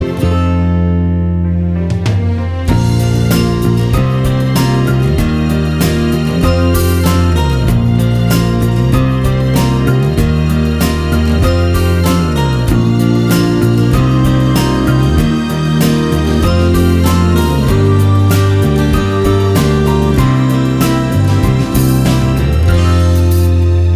Duet Version Duets 3:47 Buy £1.50